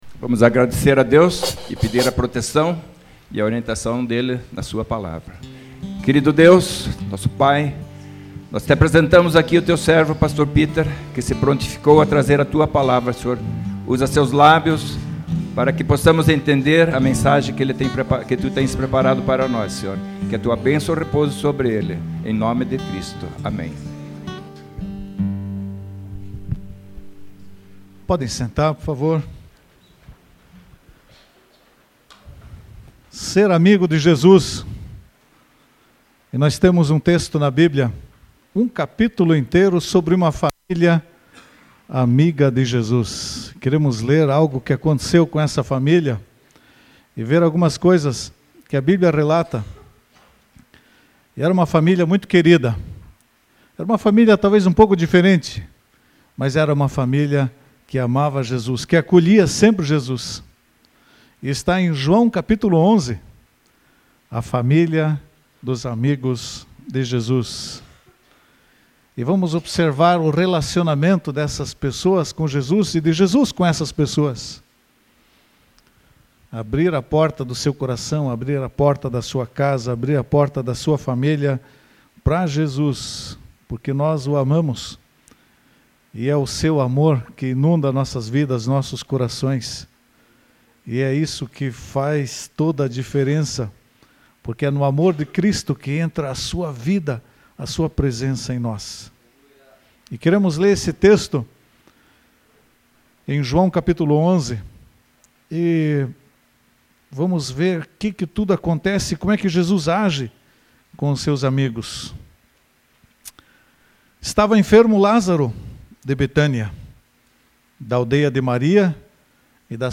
Confira a mensagem e os testemunhos do CULTO ESPECIAL DE BATISMO da IEMAV.